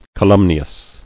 [ca·lum·ni·ous]